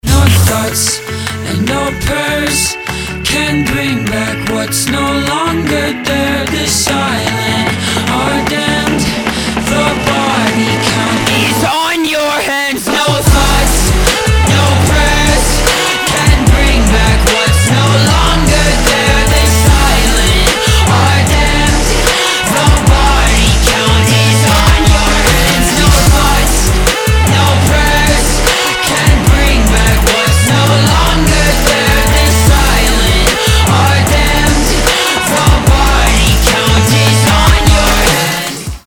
Rap-rock
Trap
Bass
Рэп-рок